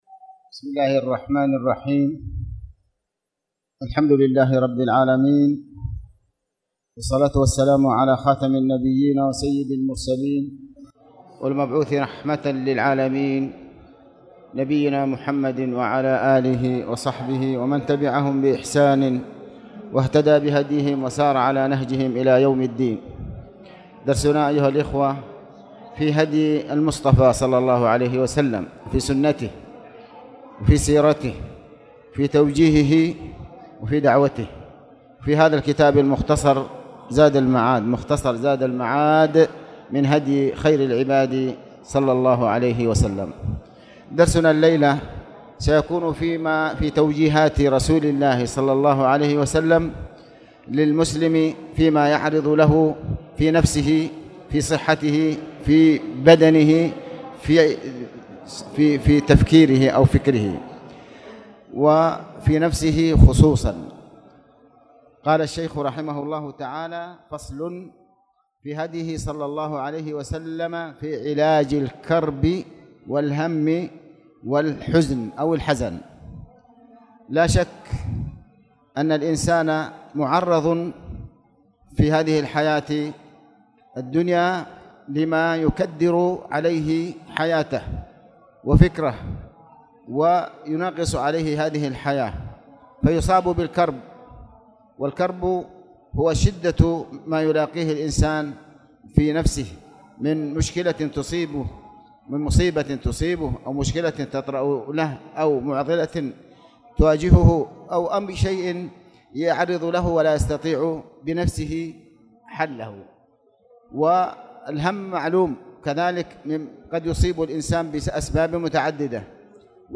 تاريخ النشر ٥ شعبان ١٤٣٧ هـ المكان: المسجد الحرام الشيخ: علي بن عباس الحكمي علي بن عباس الحكمي هديه صلى الله عليه وسلم في علاج الهم والكرب والحزن